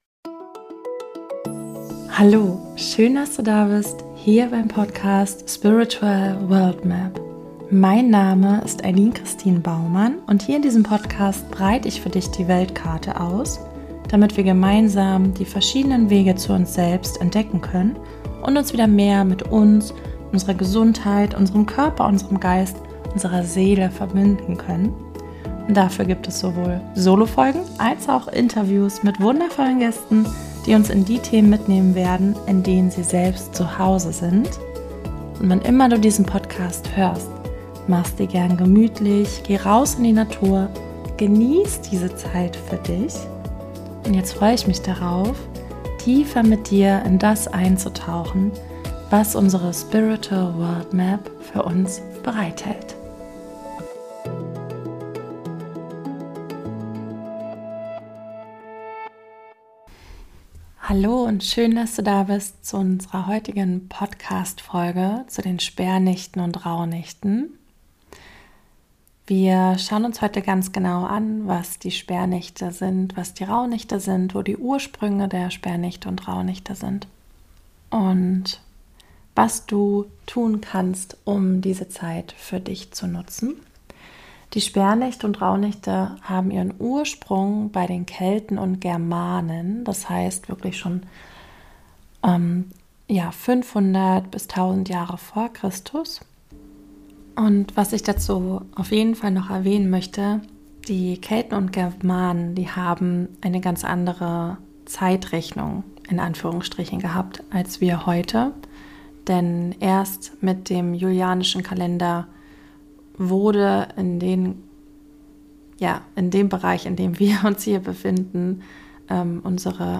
Du bekommst einen ersten Überblick über die Herkunft und Traditionen, die mit dieser Zeit verbunden sind. Dich erwartet auch eine Meditation zu den Sperrnächten, um das vergangene Jahr in Frieden abzuschließen.